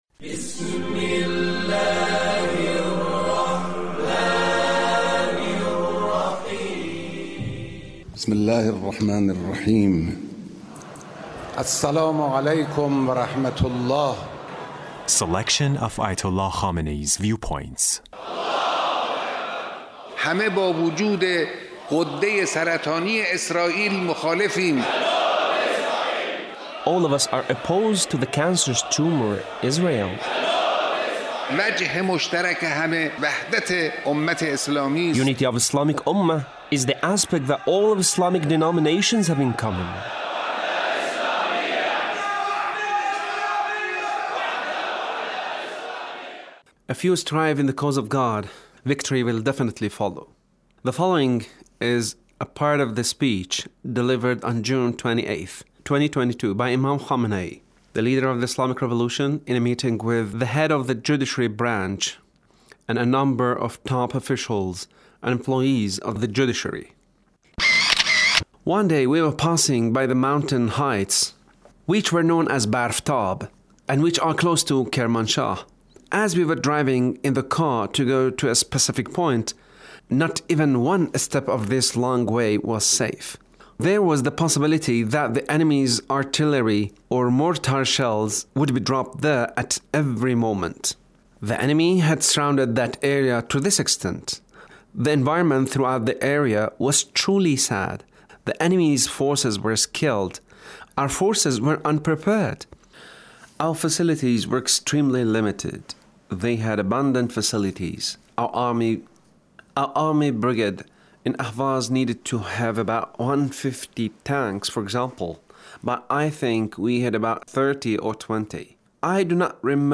Leader's Speech with Judiciary Officials